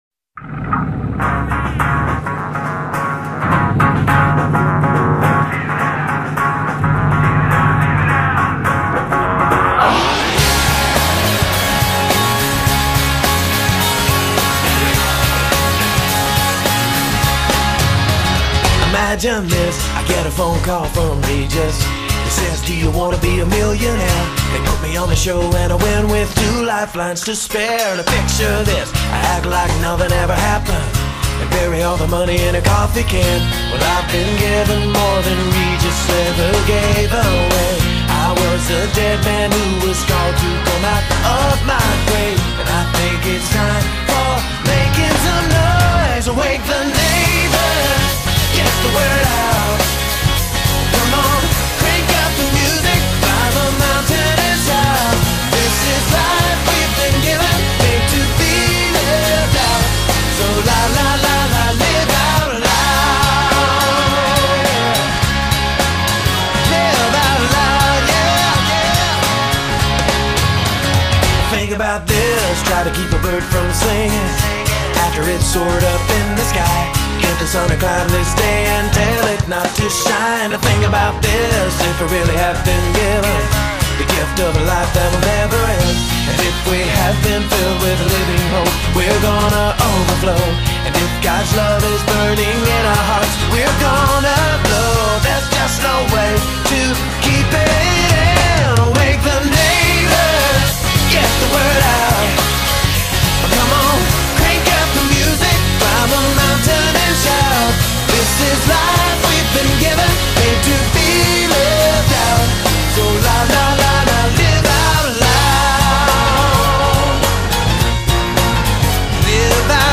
BPM52-105
Audio QualityCut From Video